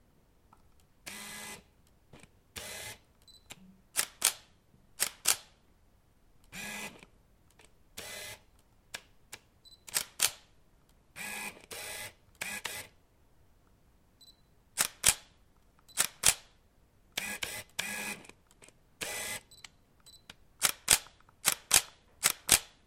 Звуки зума камеры
Эта коллекция включает различные варианты работы моторчика объектива: плавное и резкое приближение, отдаление, фокусировку.
Звук зума камеры для монтажа